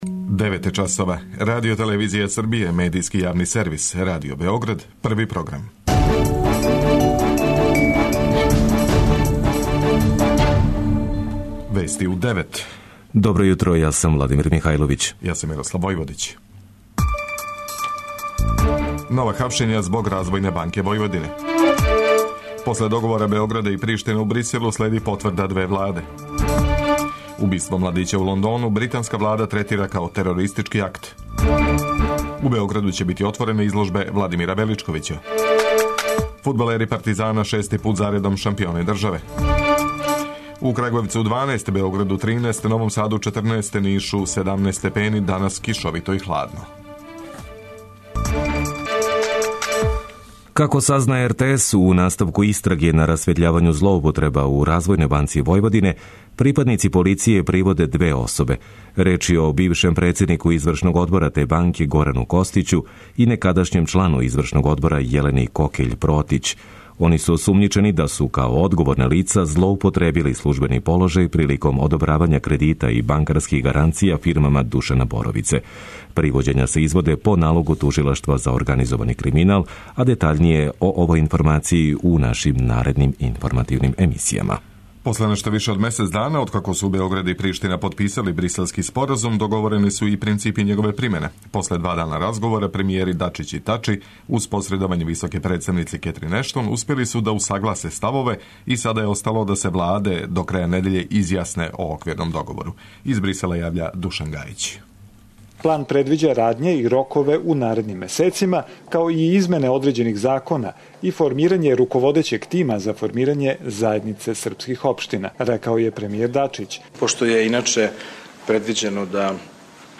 преузми : 9.87 MB Вести у 9 Autor: разни аутори Преглед најважнијиx информација из земље из света.